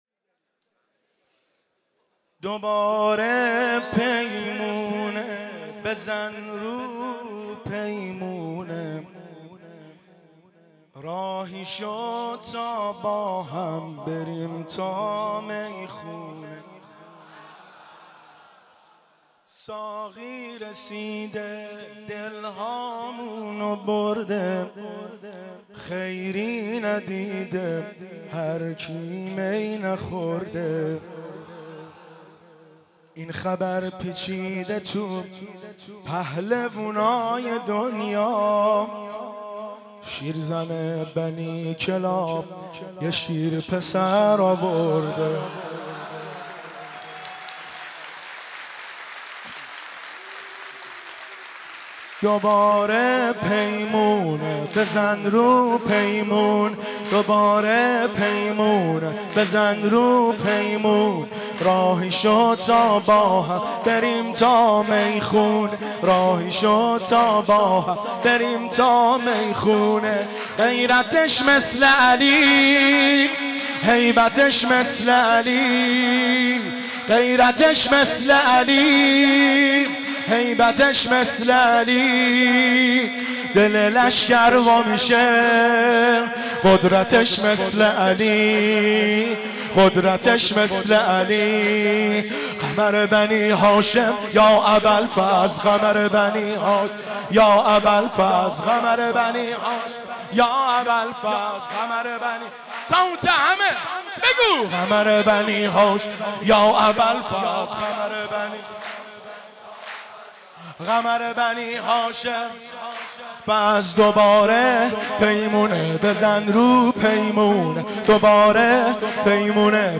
مداحی
در هیات بین الحرمین تهران برگزار شد